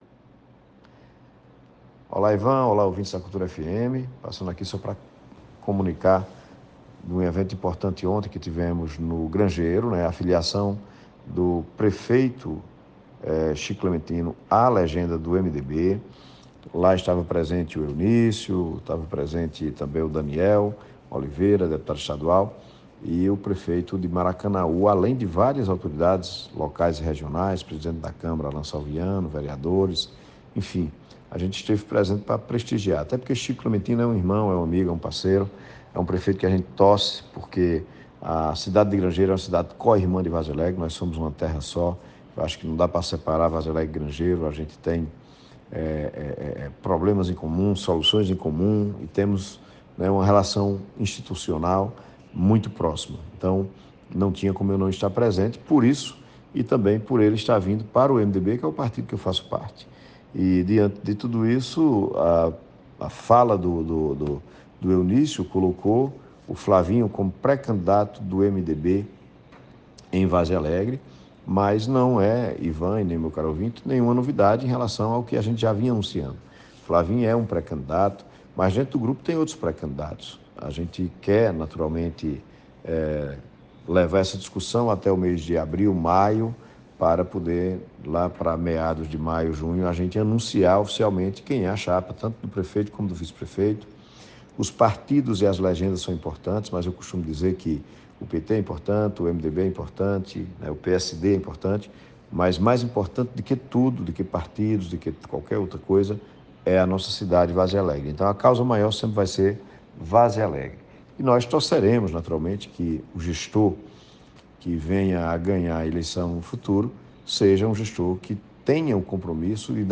PREFEITO-ZE-HELDER.mp3